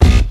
808s
neptunesbass4.wav